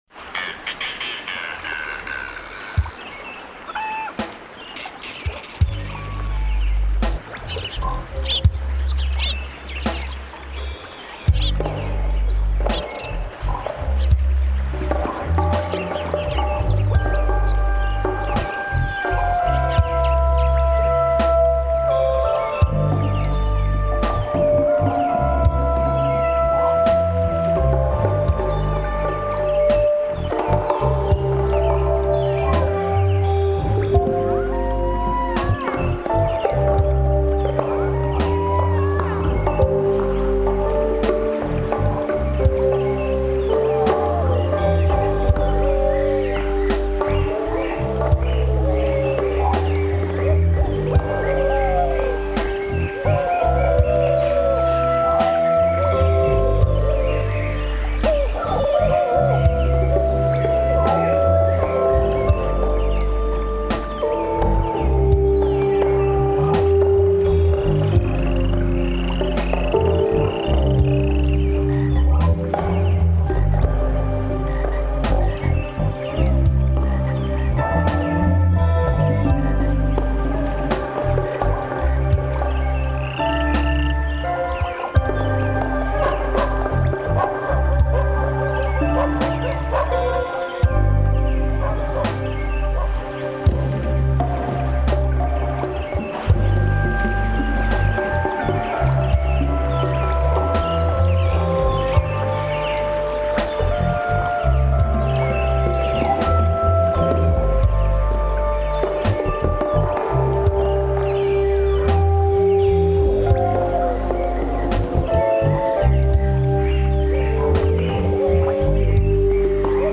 long summer-evening spiritual lounge music.